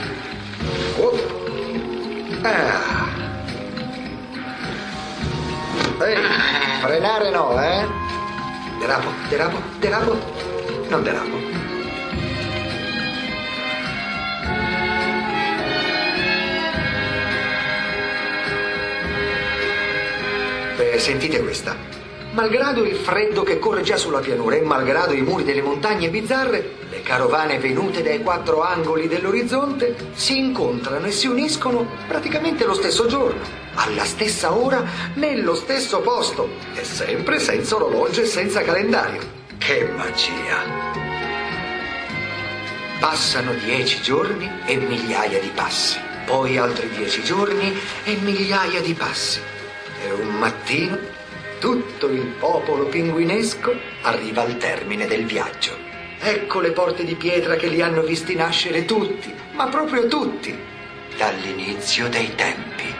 voce di Rosario Fiorello nel film documentario "La marcia dei pinguini", in cui č la voce narrante.